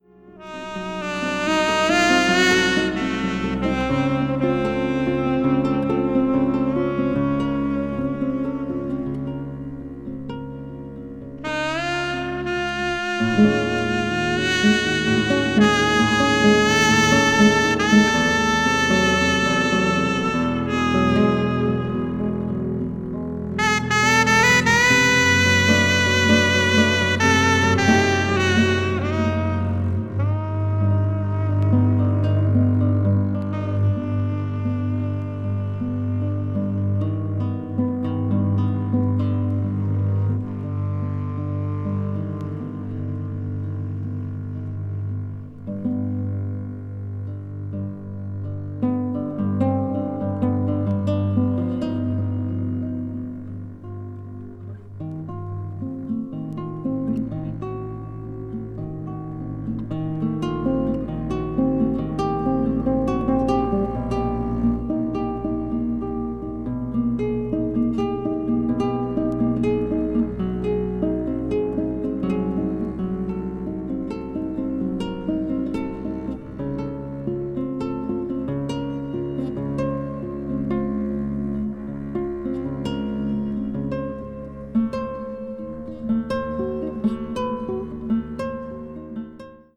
北欧の深い森のような情景が描かれた美しい音楽で、奏でられるすべての音がじんわりと染みてくるとても静かな世界。
contemporary jazz   ethnic jazz   spiritual jazz